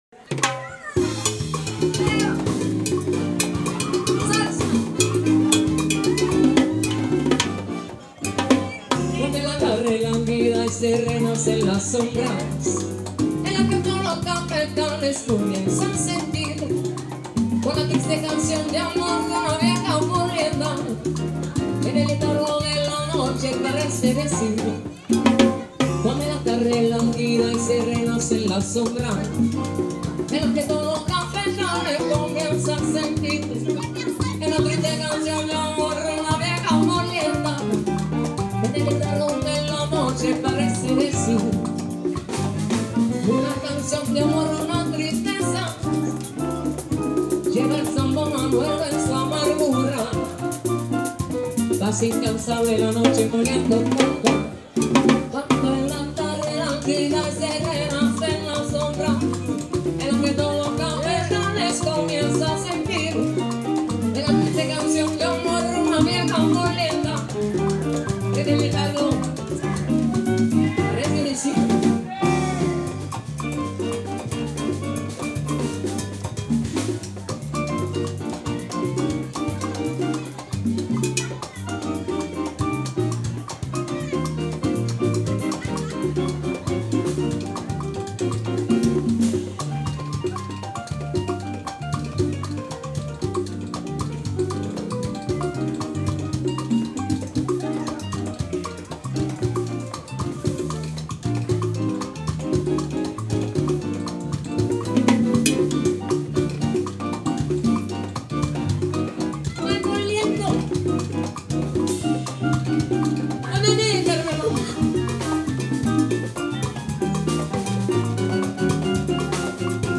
LIVE EN PUBLIC (1983 - 2020) - Un résumé...
Bodeguita del Havana (Nice) 31 Dec 1999